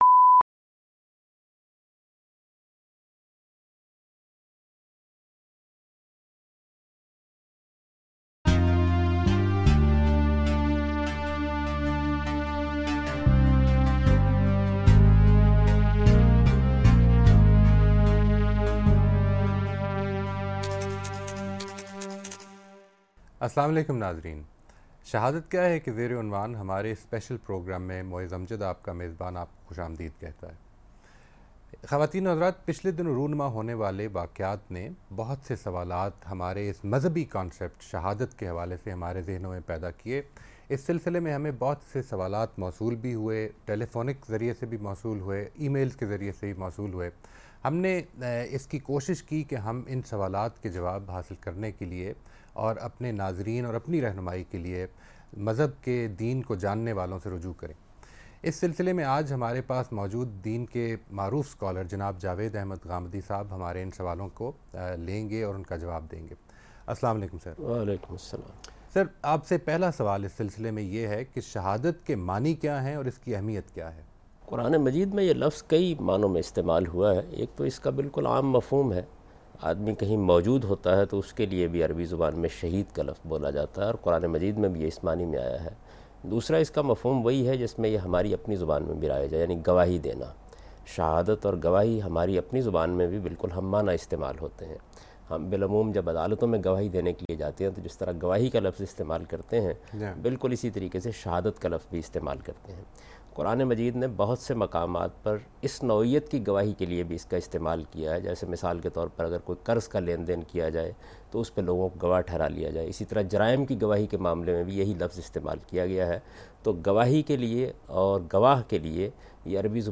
Discussion about "What is Shahadah?" with Javed Ahmad Ghamidi.